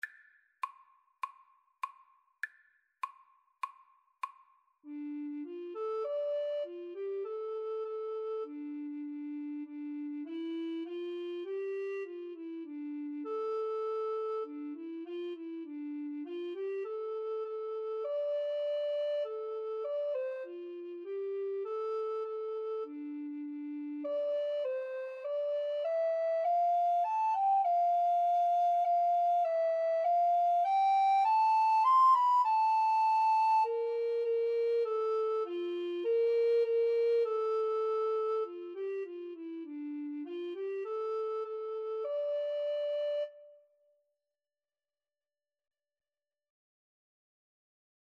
Free Sheet music for Recorder Duet
D minor (Sounding Pitch) (View more D minor Music for Recorder Duet )
Moderato
Classical (View more Classical Recorder Duet Music)